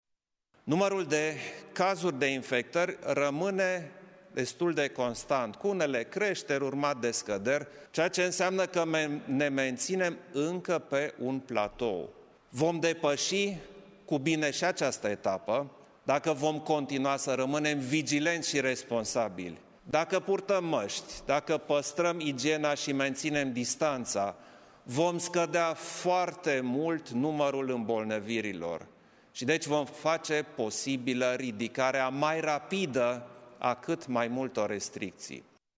Declarații ale președintelui Klaus Iohannis la finalul ședintei CSAT
Președintele s-a referit și la evoluția pandemiei de coronavirus din țara noastră și  ne-a îndemnat să fim în continuare prudenți: